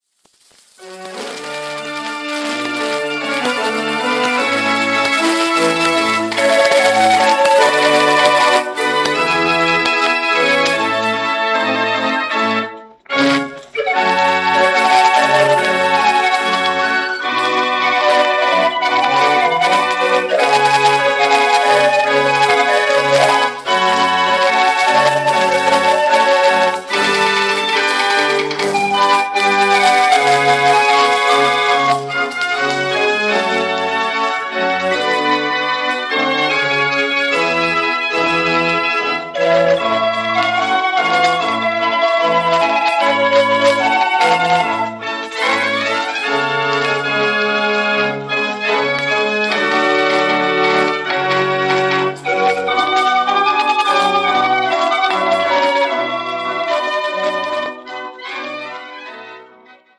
Formaat 78-toerenplaat
heeft een zachte en
met een eigen mechanische klank die direct herkenbaar is